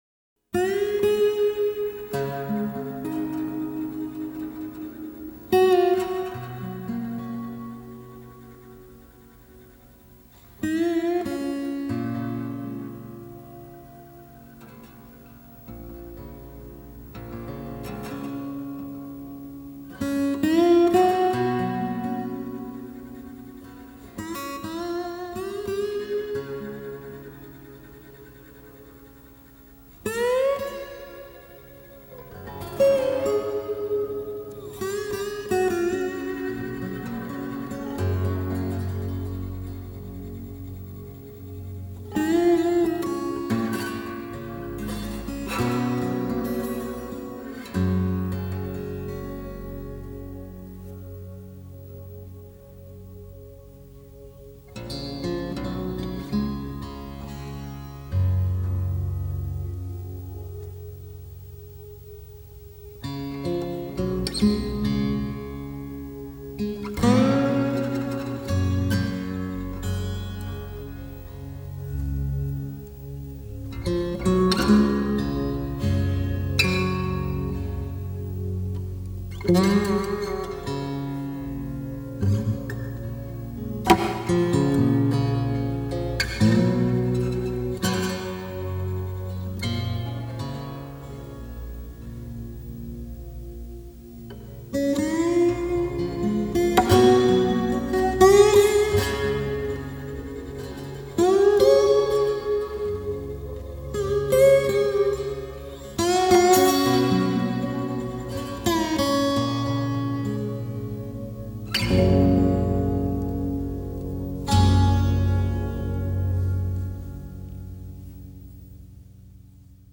每每听到那空灵、孤独的滑棒吉他弦音，听者的思绪会飘到美国西部辽阔而荒芜的沙漠，最后消失在低垂的夜幕中。
音樂類型：電影原聲帶(電影配樂)